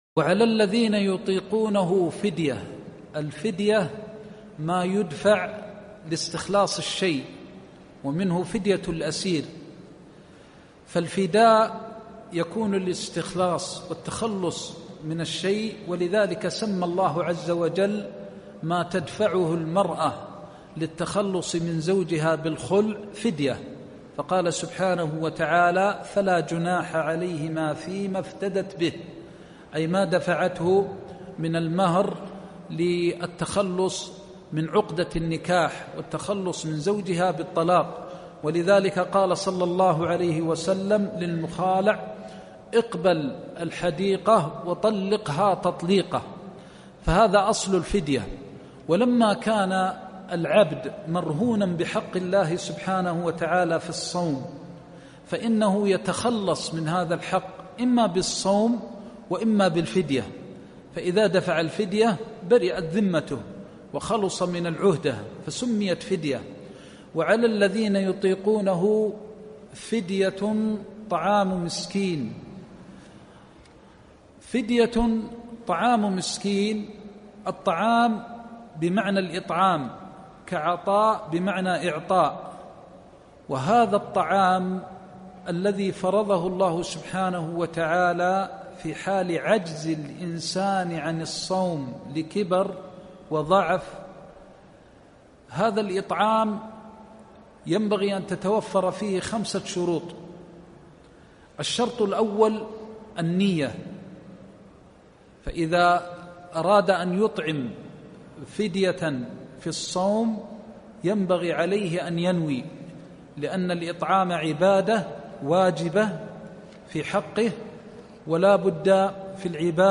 دروس تفسير آيات الصيام(الحرم المدني)-درس (3)-قوله سبحانه(فدية طعام مسكين)